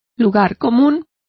Complete with pronunciation of the translation of truisms.